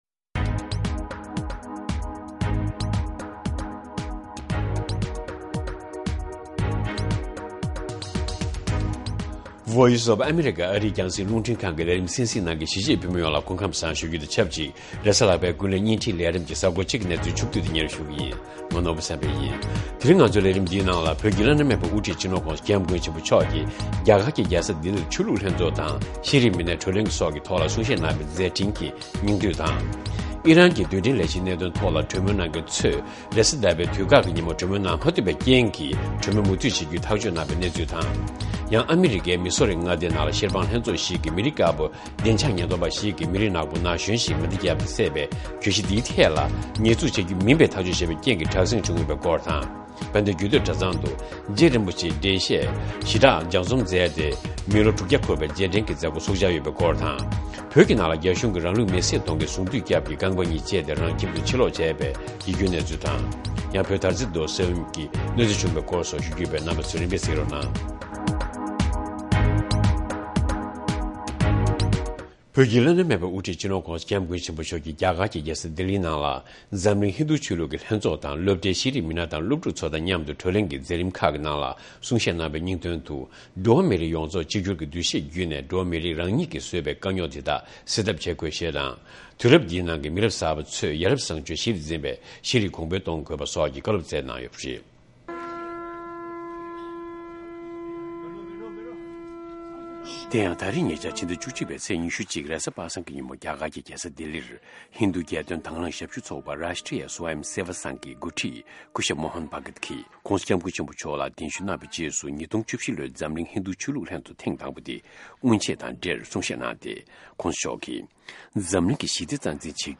དགོང་དྲོའི་གསར་འགྱུར།